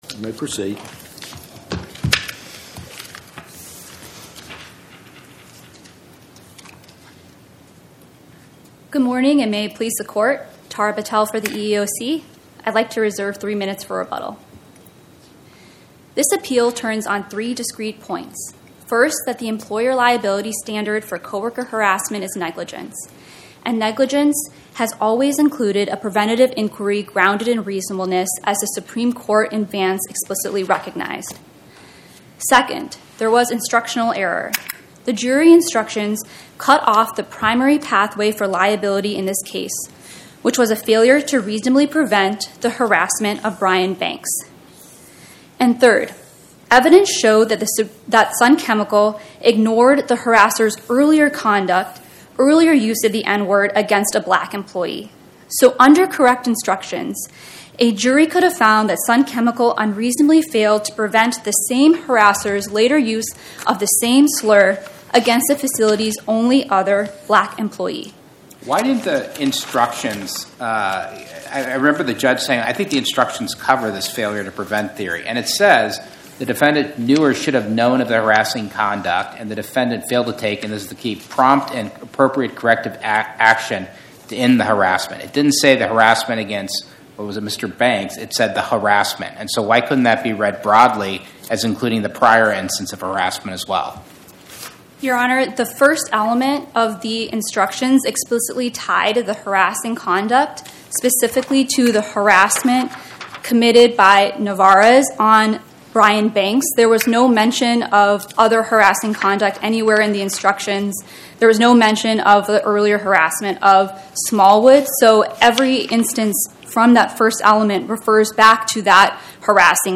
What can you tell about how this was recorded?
My Sentiment & Notes 25-1318: EEOC vs Sun Chemical Corporation Podcast: Oral Arguments from the Eighth Circuit U.S. Court of Appeals Published On: Wed Jan 14 2026 Description: Oral argument argued before the Eighth Circuit U.S. Court of Appeals on or about 01/14/2026